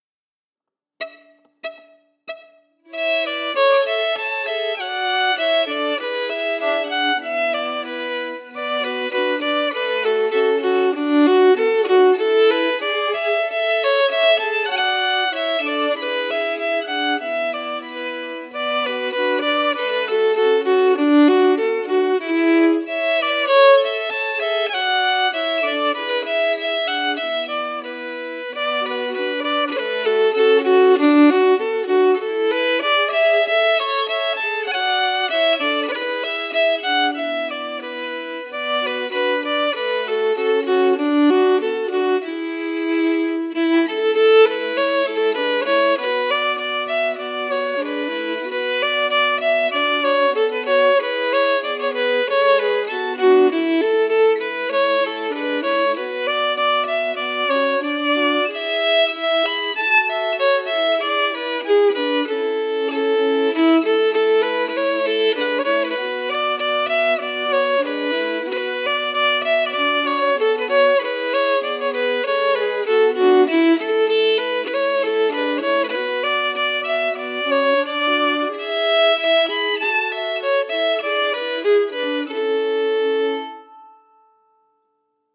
Key: A
Form: Slow reel
Melody emphasis
Region: Northumberland, England